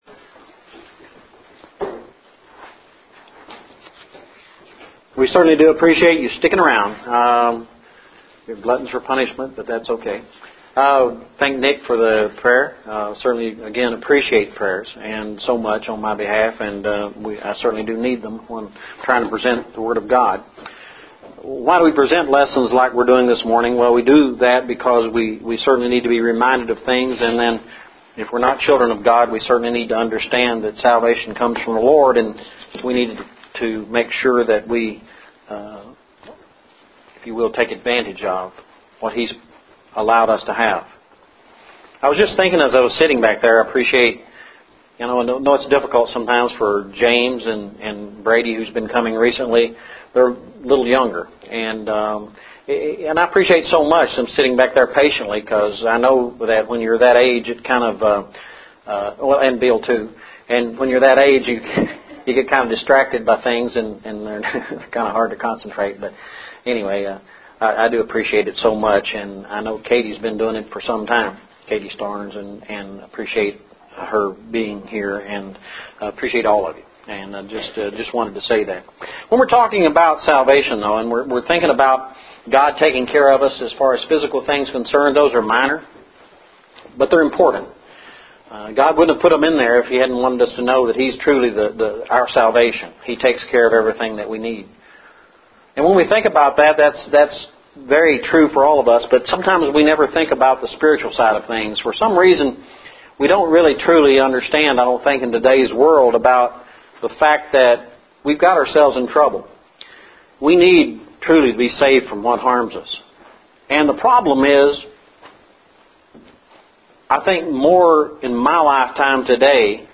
Salvation Lesson – 01/23/11
Recorded Lessons